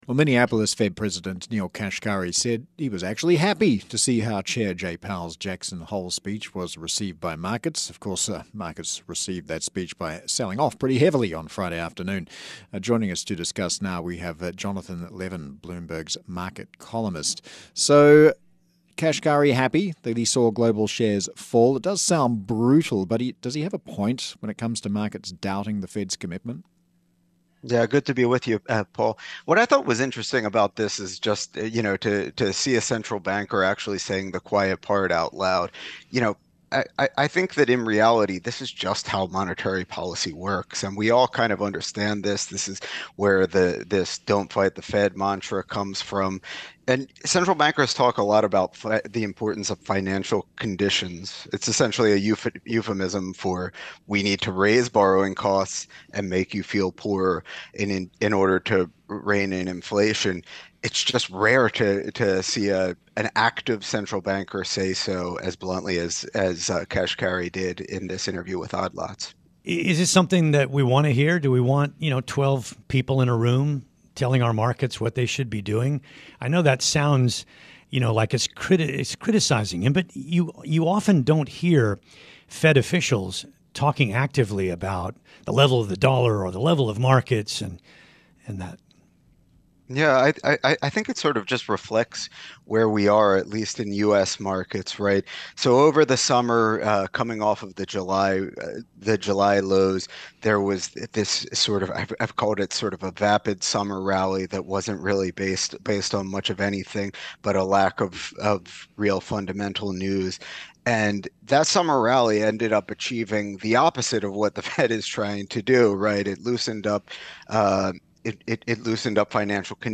discusses Kashkari's comments with hosts
on Bloomberg Radio